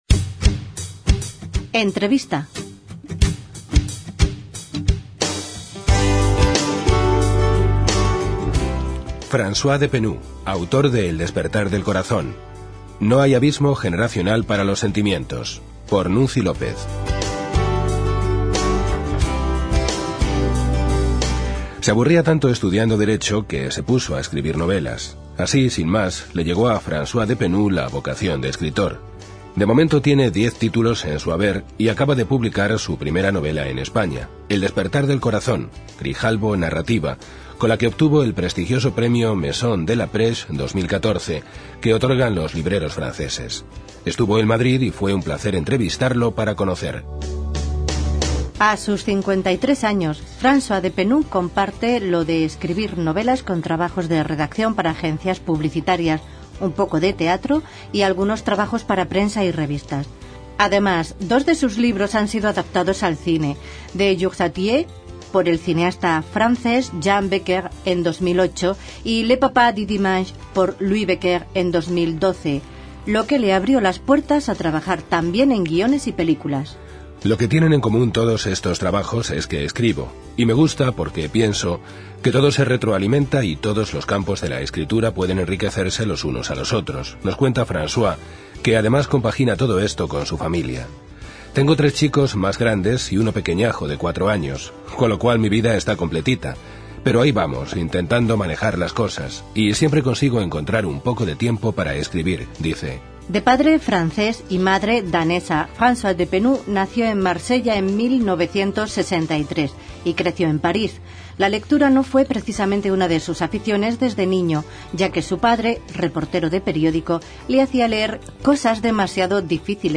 06 ENTREVISTA.mp3